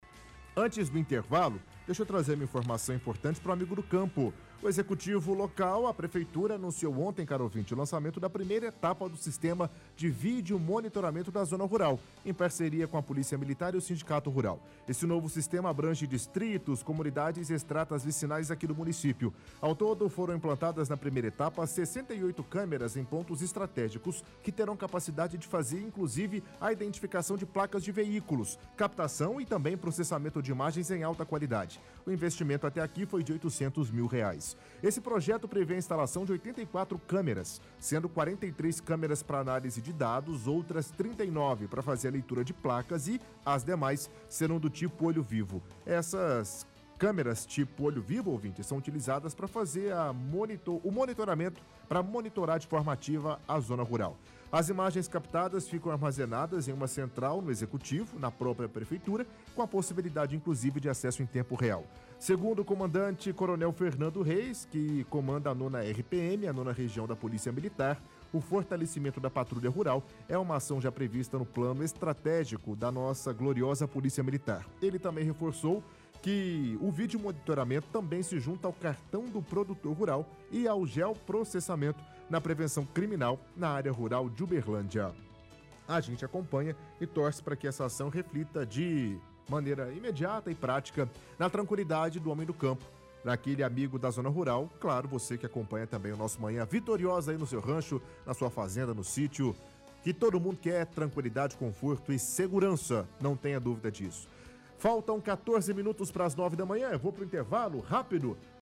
– Leitura de reportagem do site Diário de Uberlândia.